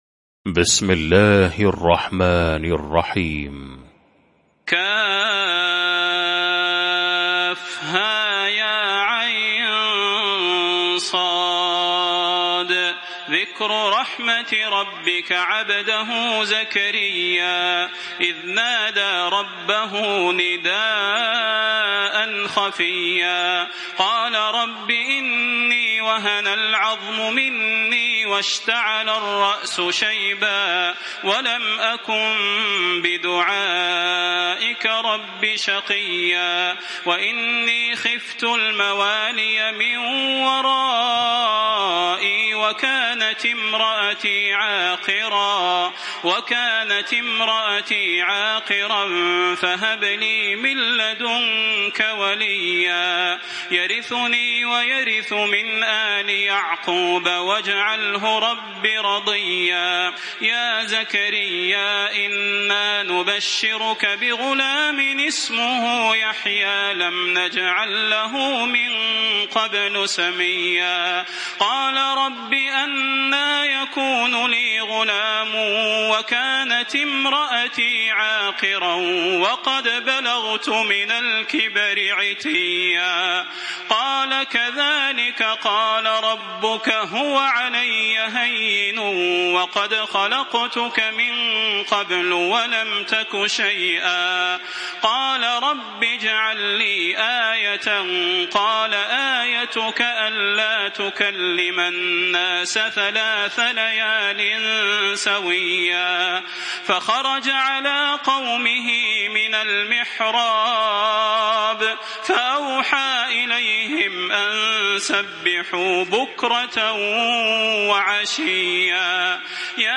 المكان: المسجد النبوي الشيخ: فضيلة الشيخ د. صلاح بن محمد البدير فضيلة الشيخ د. صلاح بن محمد البدير مريم